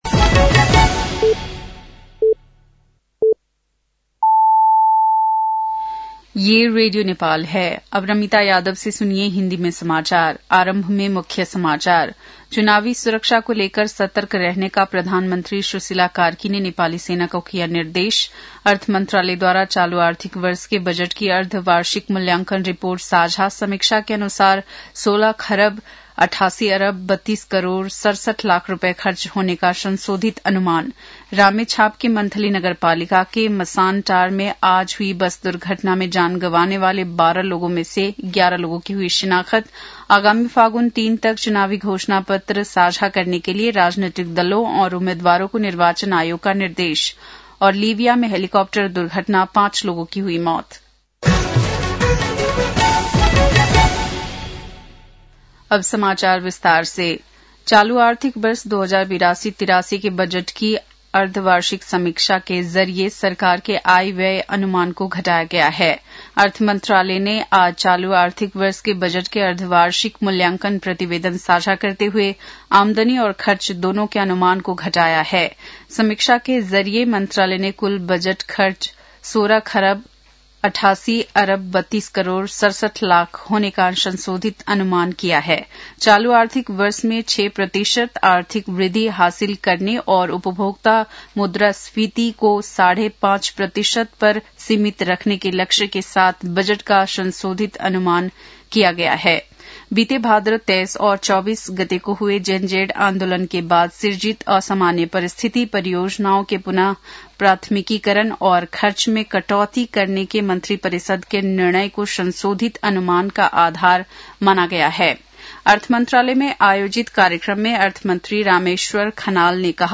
बेलुकी १० बजेको हिन्दी समाचार : २७ माघ , २०८२